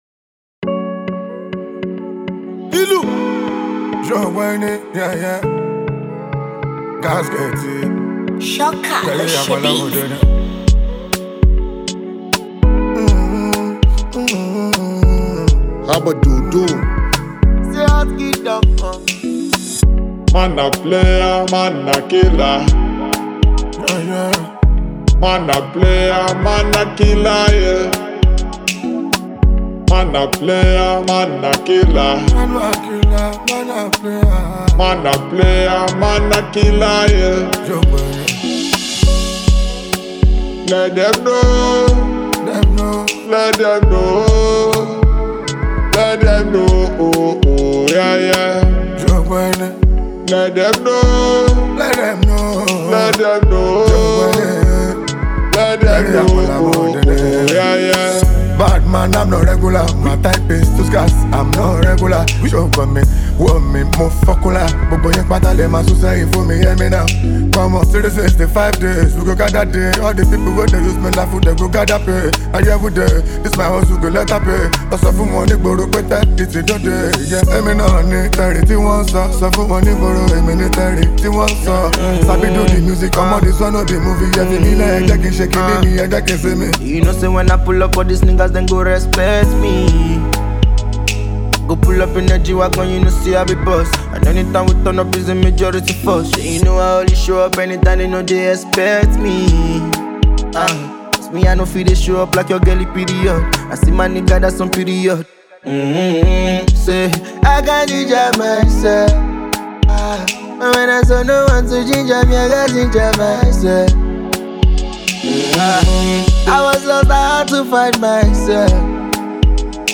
The highly anticipated remix